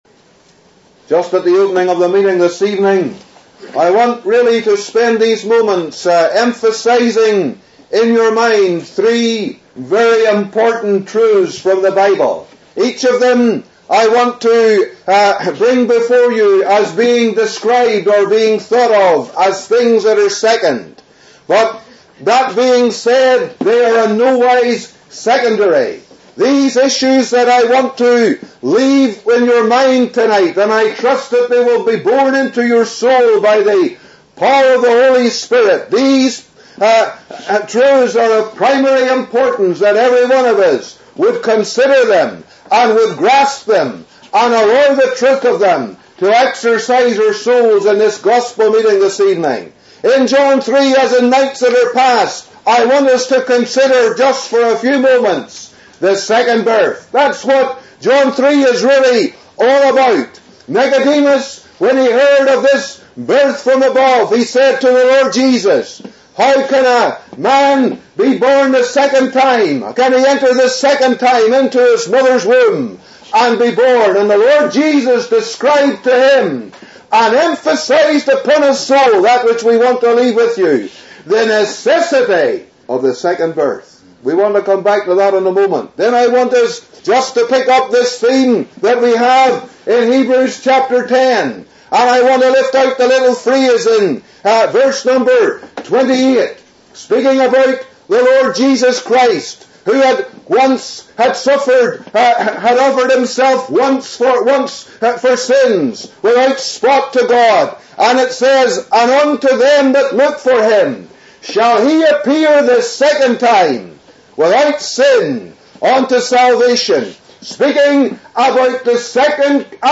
(Messages preached Sunday 9th August 2009)